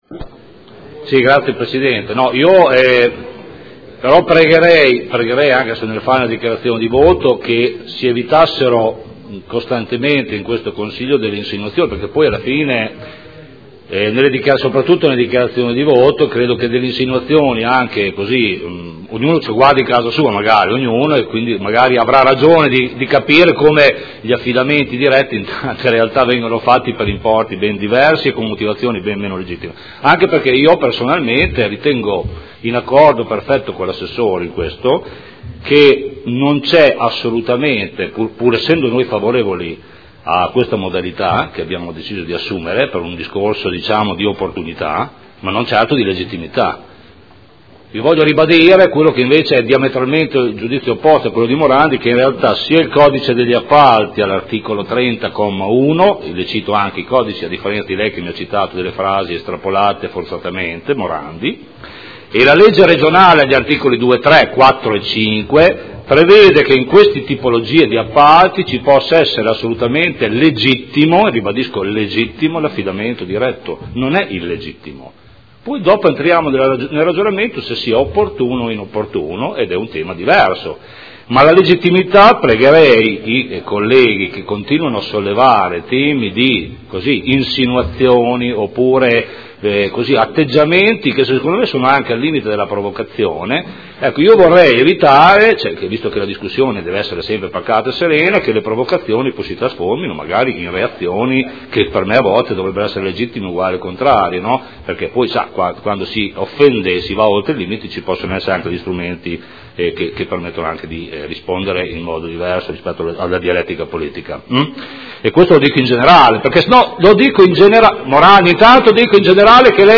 Giancarlo Campioli — Sito Audio Consiglio Comunale
Proposta di deliberazione: Concessione gestione piscina Dogali: indirizzi. Dichiarazioni di voto